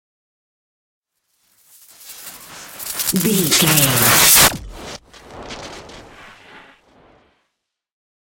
Appear sci fi electricity
Sound Effects
futuristic
tension
whoosh